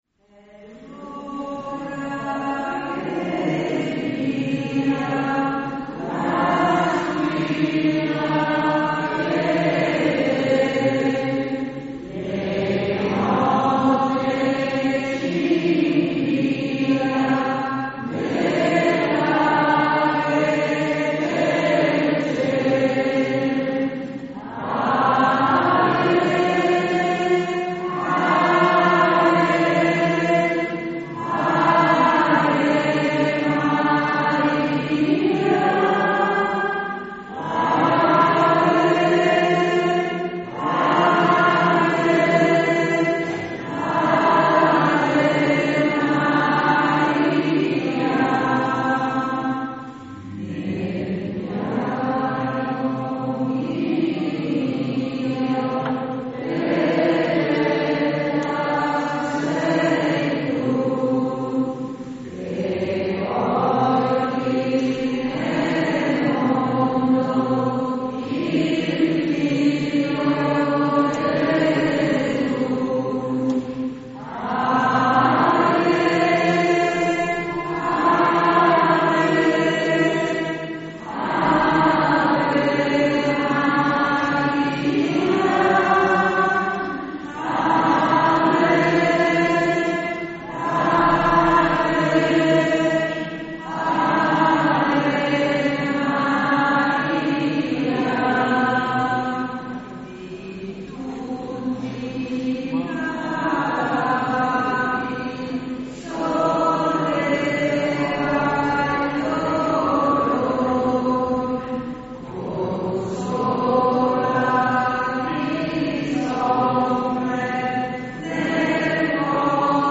Rualis d Cividale (UD), 15 Settembre 2024
Santa Messa nella XXIV Domenica del T. O.
ALLELUIA